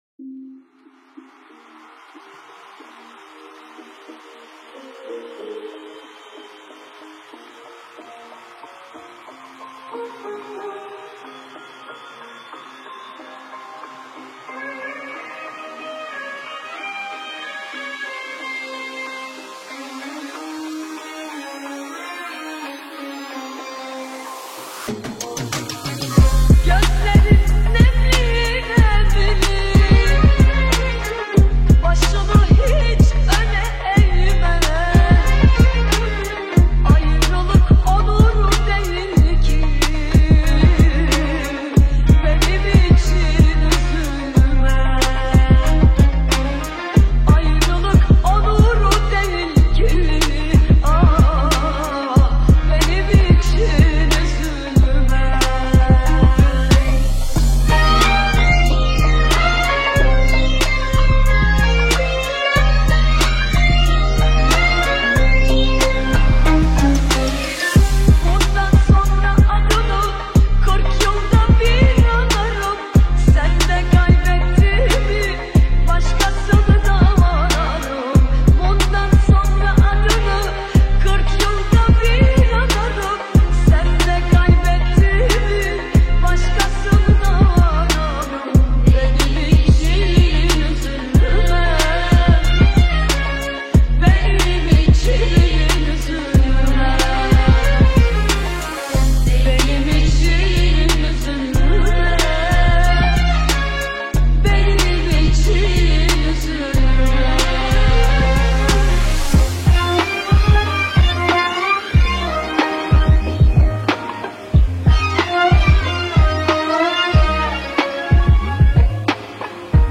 آهنگ ترکیه ای آهنگ غمگین ترکیه ای آهنگ نوستالژی ترکیه ای